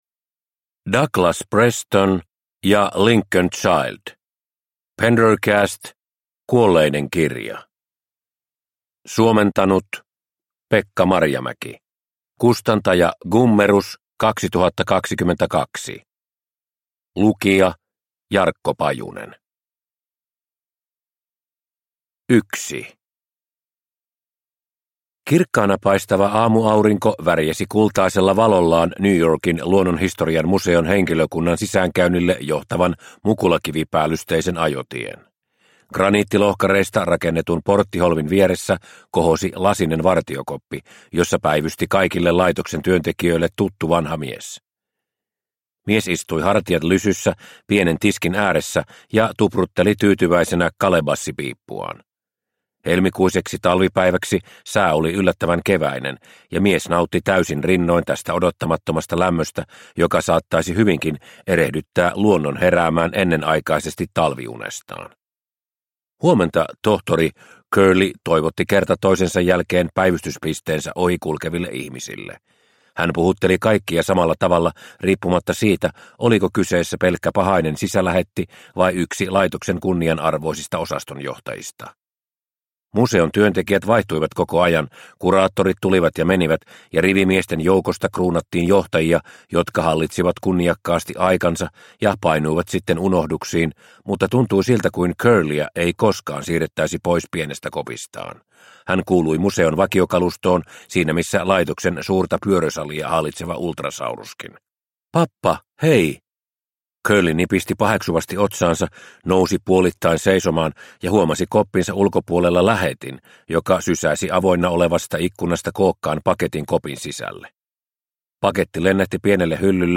Kuolleiden kirja – Ljudbok – Laddas ner